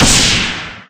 Ice2.ogg